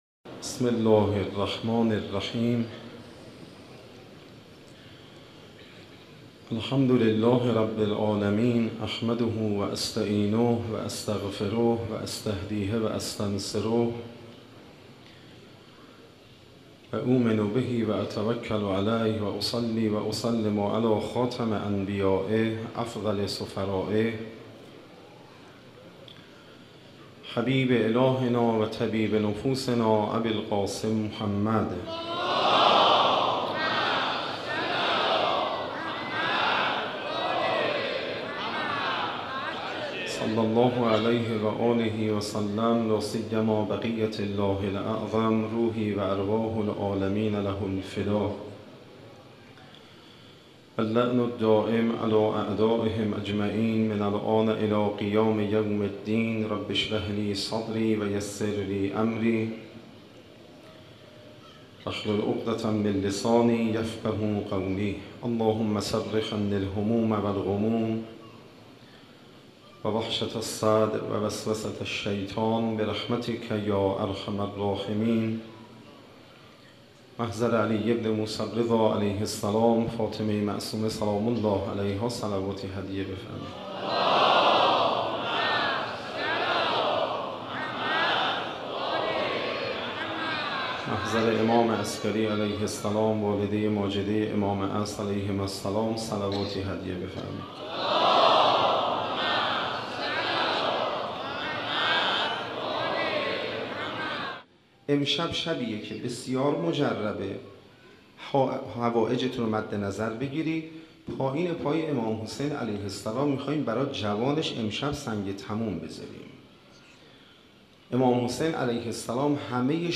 مناسبت : شب دوم محرم
Moharrame 93, Shabe 08, Sokhanrani.mp3